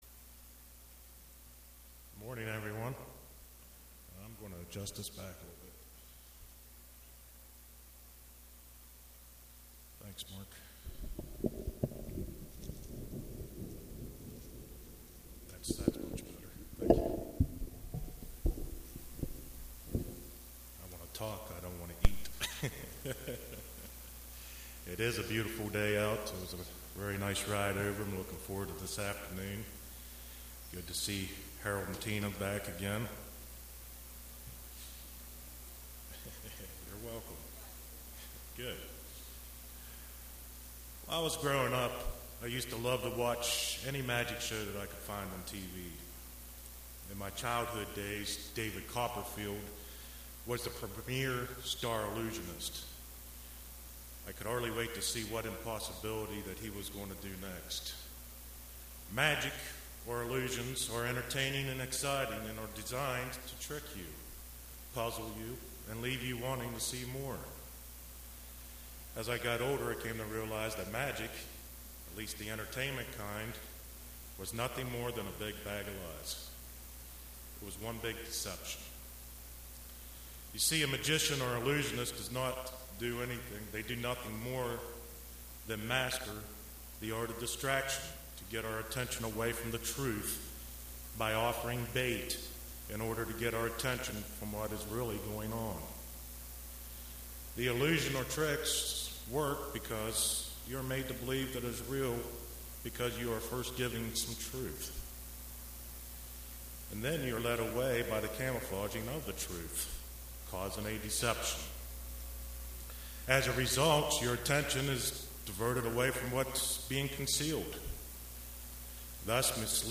Print Don't Fall Prey to Deception UCG Sermon Studying the bible?